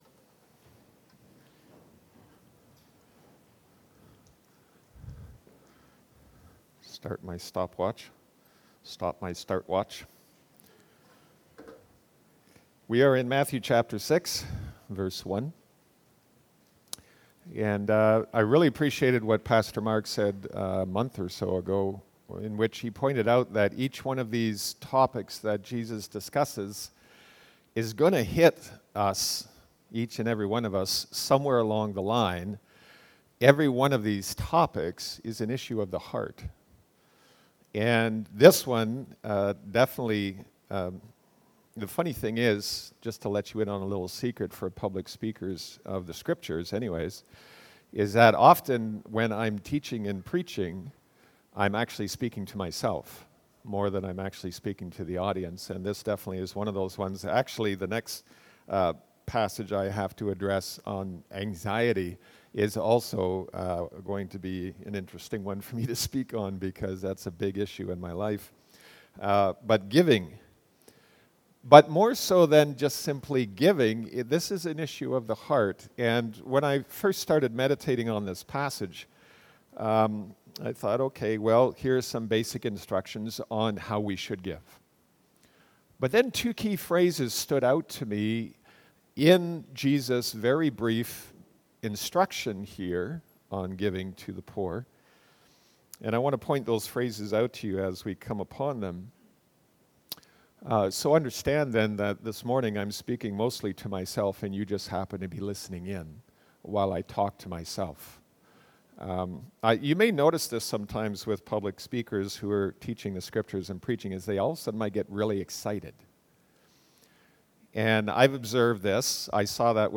Jan 05, 2020 Guarding Against Spiritual Pride (Matthew 6:1-4) MP3 SUBSCRIBE on iTunes(Podcast) Notes Discussion Sermons in this Series Loading Discusson...